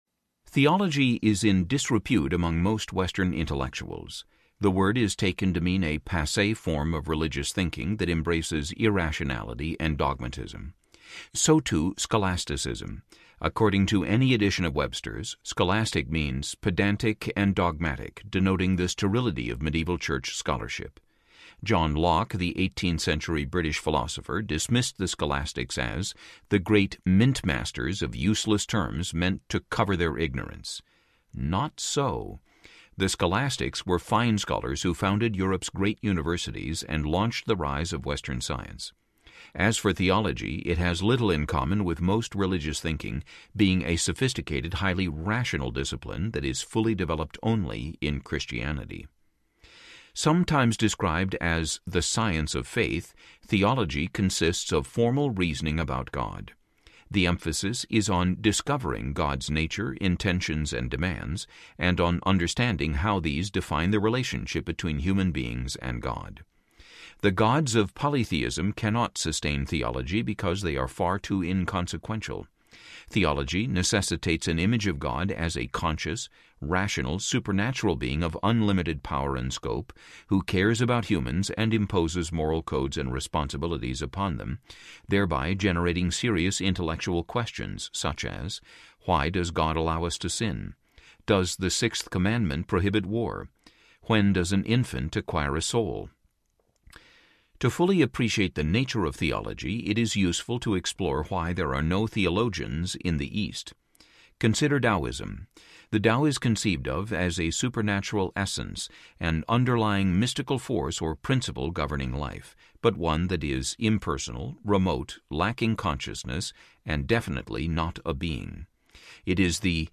The Victory of Reason Audiobook